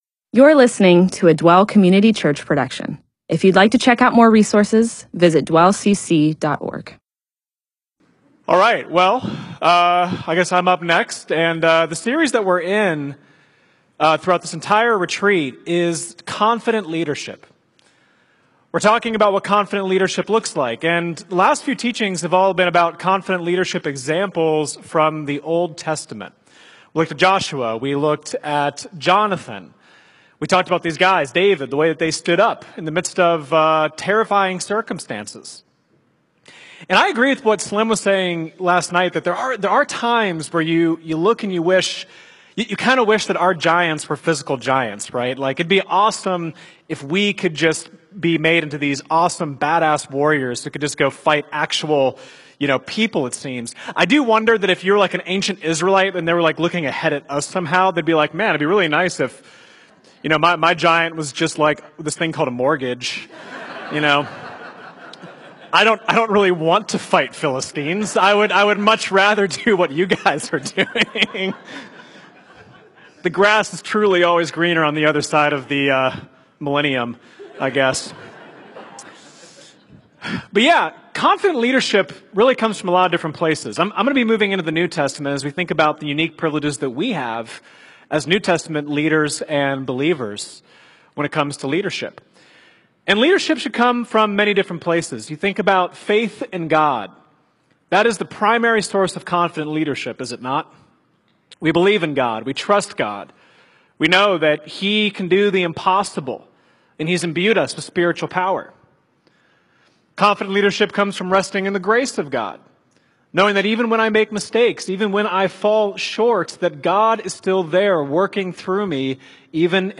MP4/M4A audio recording of a Bible teaching/sermon/presentation about Romans 12:1-5.